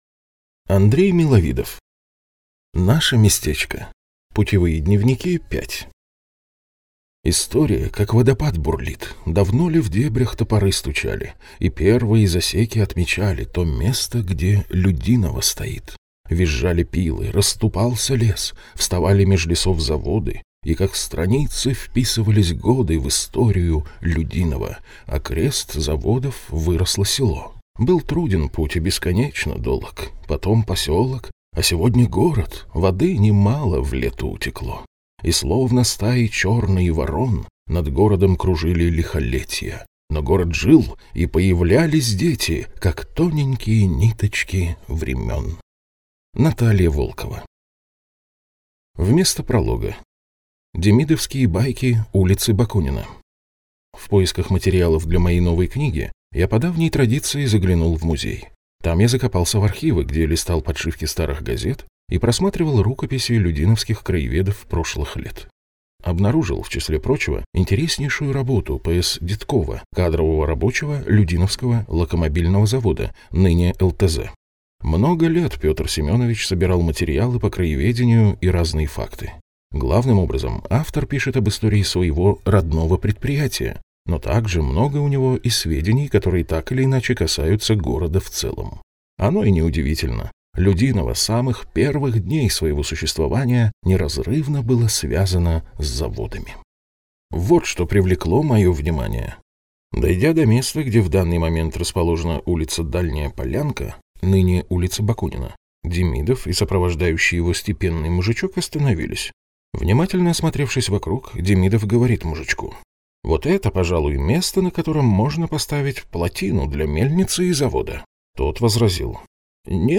Аудиокнига Наше местечко. Путевые дневники – 5 | Библиотека аудиокниг